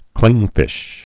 (klĭngfĭsh)